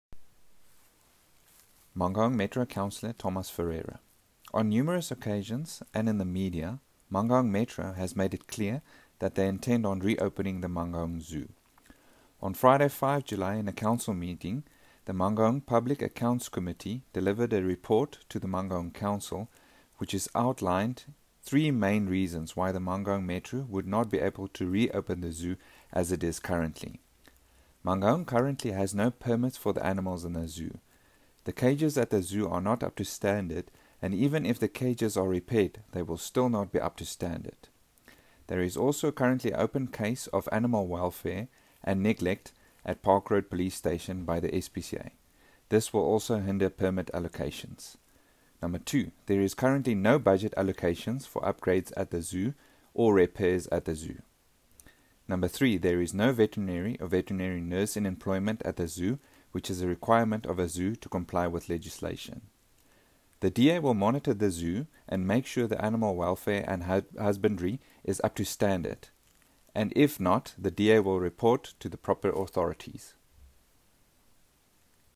English and Afrikaans soundbites by Cllr Thomas Ferreira